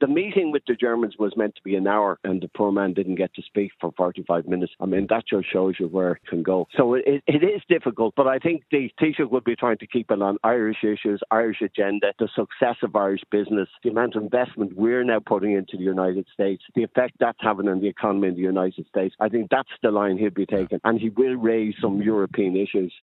Former Taoiseach Bertie Ahern says President Trump is known for going off-topic during his meetings: